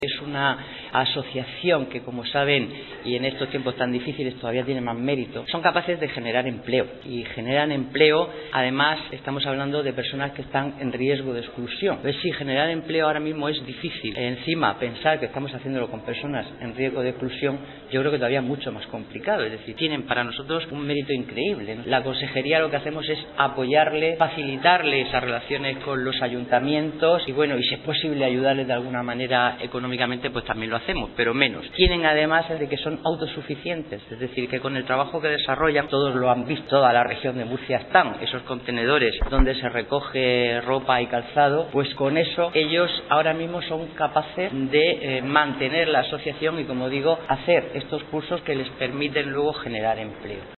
Contenidos Asociados: Declaraciones de la consejera de Sanidad y Política Social sobre las actividades para 2013 del Proyecto Abraham (Documento [.mp3] 0,77 MB) Destacados Conciliación laboral (SMAC) e-Tributos Pago a Acreedores Participación ciudadana Canal Mar Menor © Todos los derechos reservados.